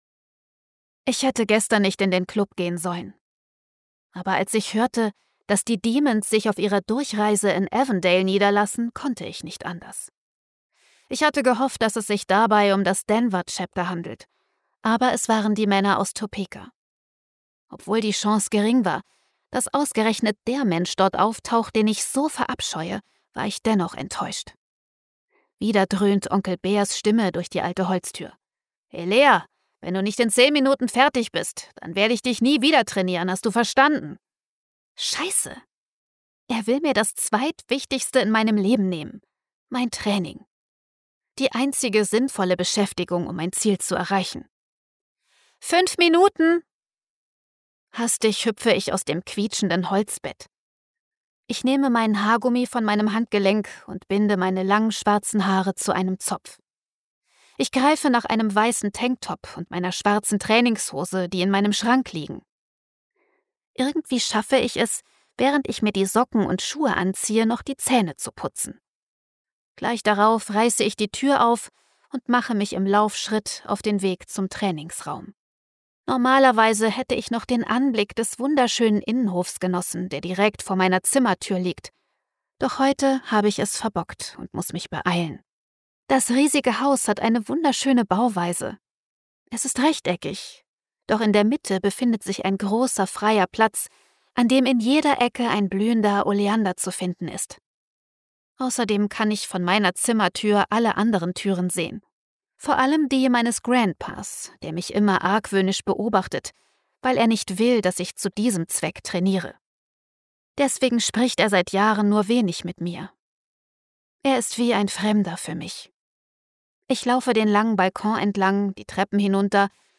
Stimmfarbe: warm, weich, zart, facettenreich